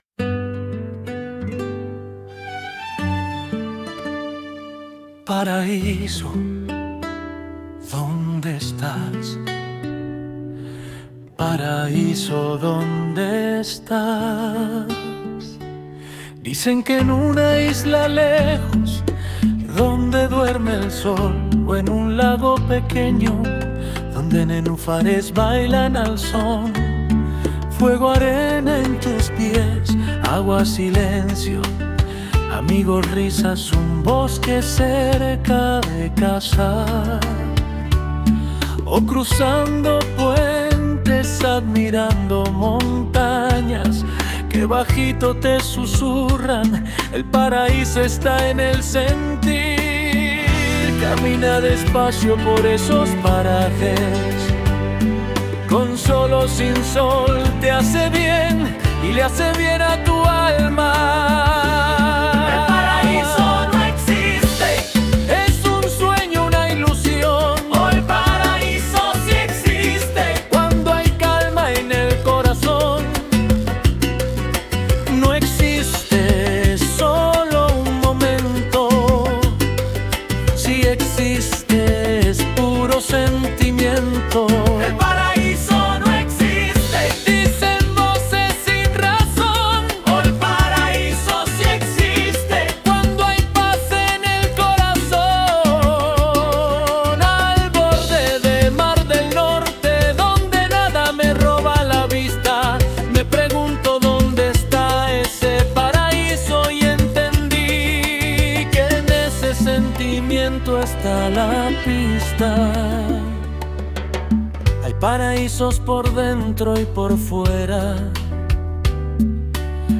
Hemos tenido seis ritmos a elegir y la salsa ganó con 16 puntos entre otros como: samba - tango - vals - rock pesado - marcha.